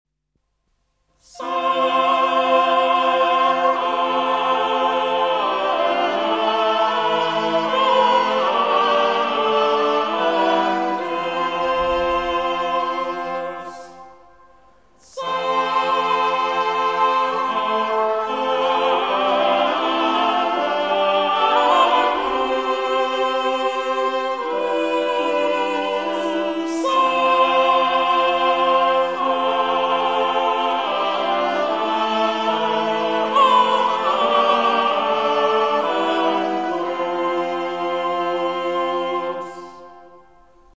(1300-1377) Het is een vierstemmige zetting van het ordinarium.
• de vierstemmige zetting (in die tijd ongebruikelijk)